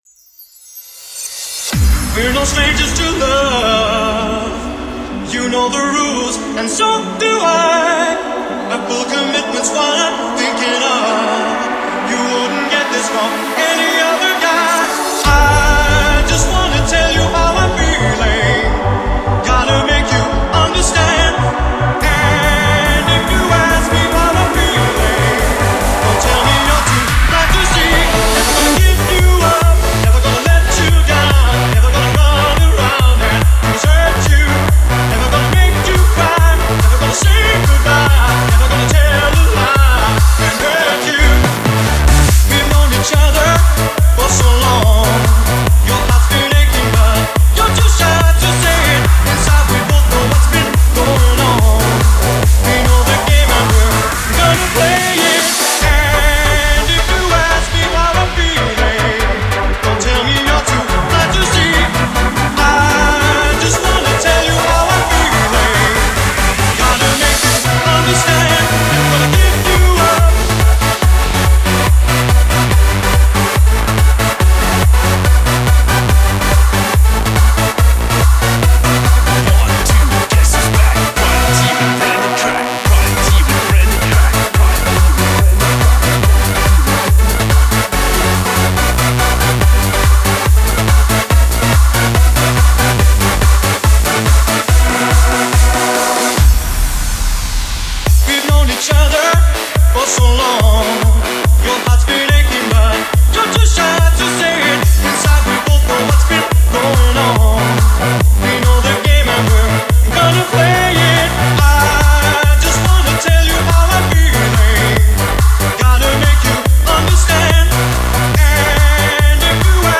Hands Up song